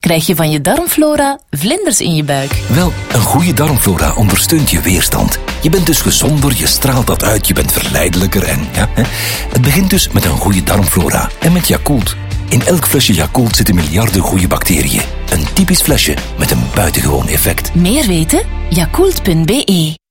Professional Flemish voice actor.
Sprechprobe: Werbung (Muttersprache):